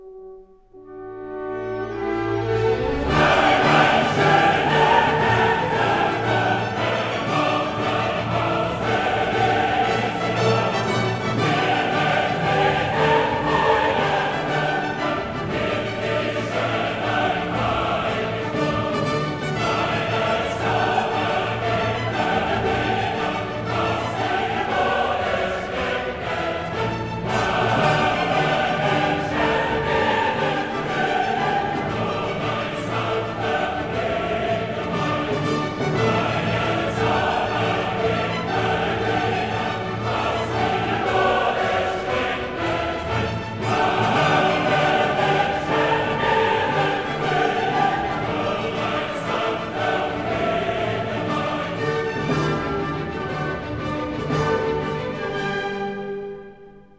in d minor
引子中神秘的空五度像是开天辟地以前的黑暗和混沌。
这个旋律第一次出现时没有歌词，仅仅由大提琴和低音提琴无伴奏地演奏出来。